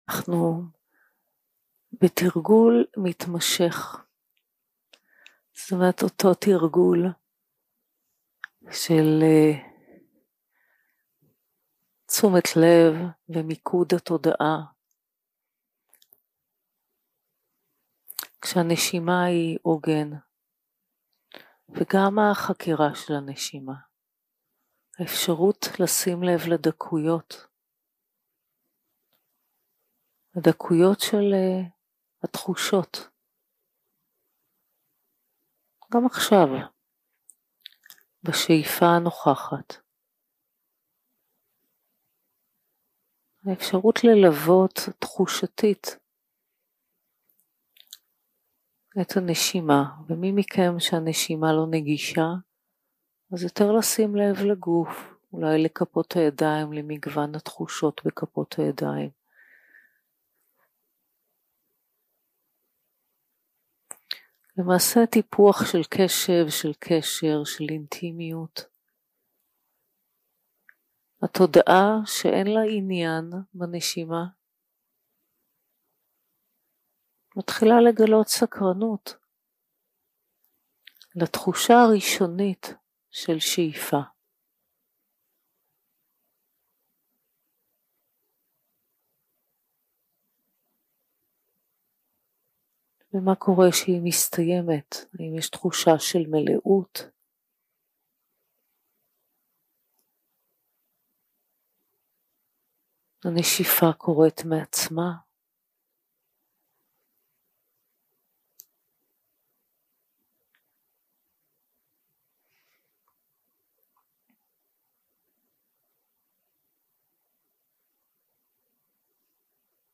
יום 3 - הקלטה 5 - בוקר - הנחיות למדיטציה - ודנא, תגובתיות והלכי רוח
יום 3 - הקלטה 5 - בוקר - הנחיות למדיטציה - ודנא, תגובתיות והלכי רוח 0:00 0:00 סוג ההקלטה: Dharma type: Guided meditation שפת ההקלטה: Dharma talk language: Hebrew